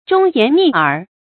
注音：ㄓㄨㄙ ㄧㄢˊ ㄋㄧˋ ㄦˇ
忠言逆耳的讀法